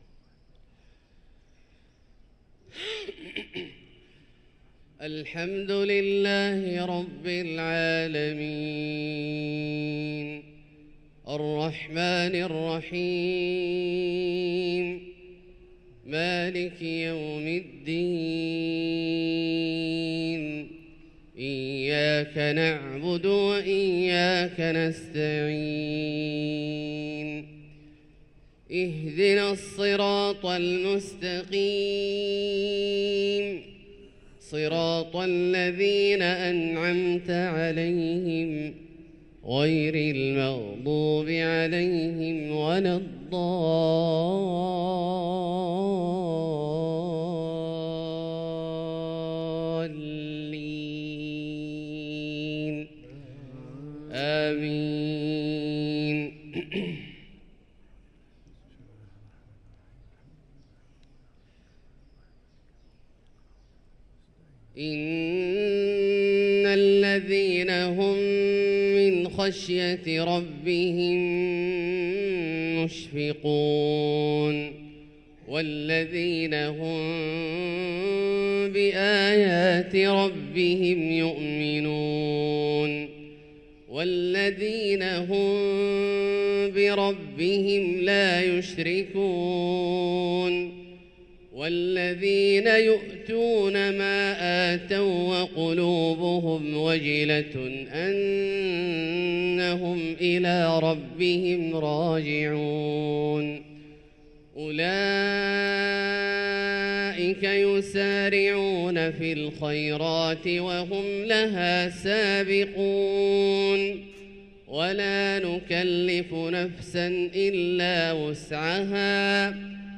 صلاة الفجر للقارئ عبدالله الجهني 26 صفر 1445 هـ
تِلَاوَات الْحَرَمَيْن .